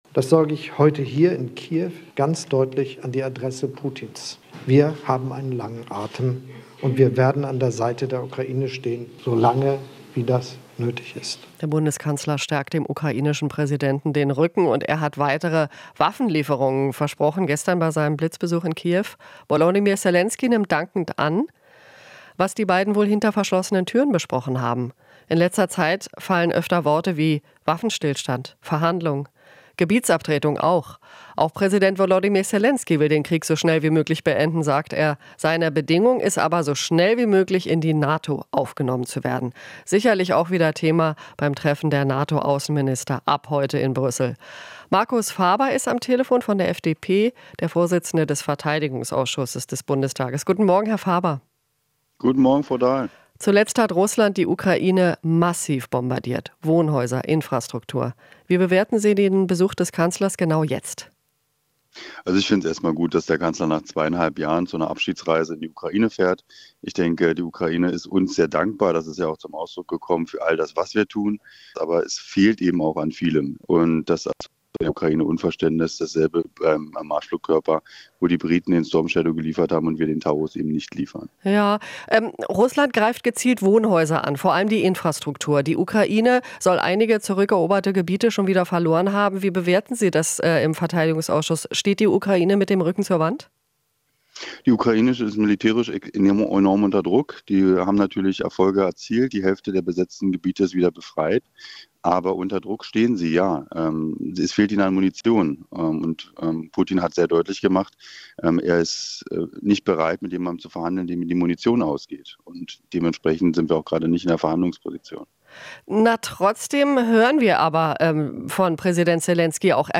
Interview - Ukraine-Krieg: Faber (FDP) warnt vor Gebietsabtretungen an Russland